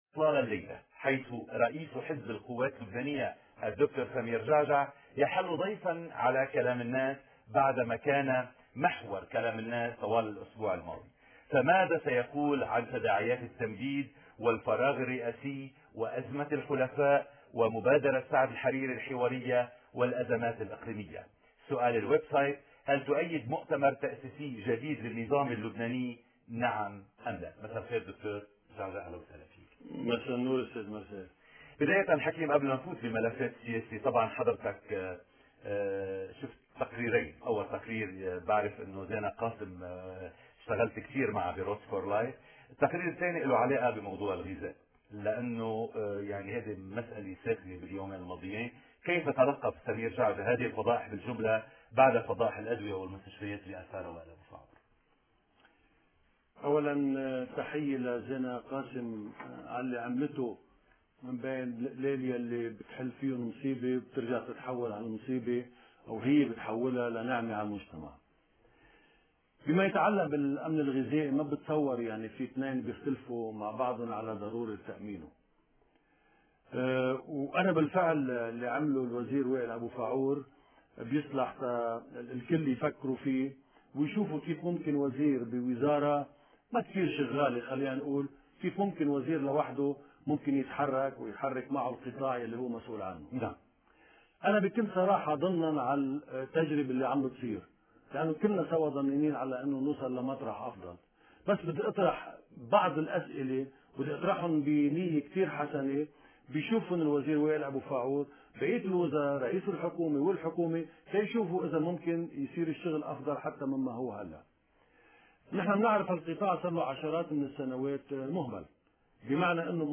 بالصوت/فورماتMP3/مقابلة الدكتور سمير جعجع مع مرسال غانم يوم أمس/14 تشرين الثاني/14